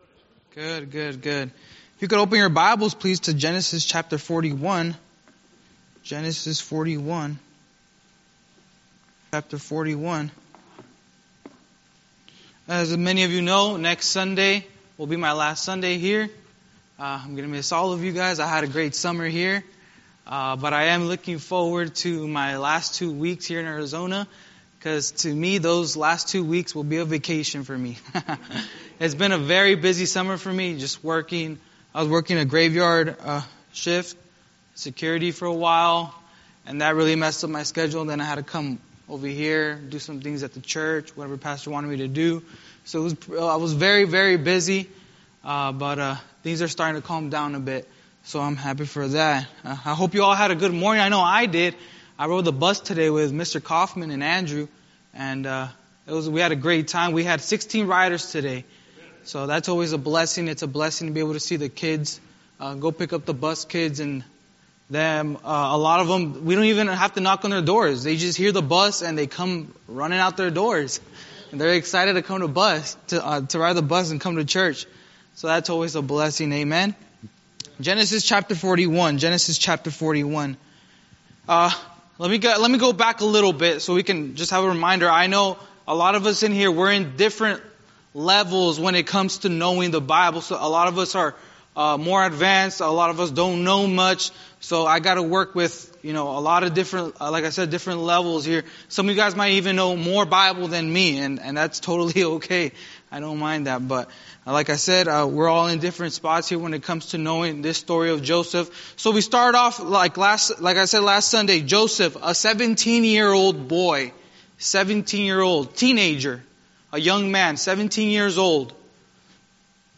Sunday School Recordings
Series: Guest Speaker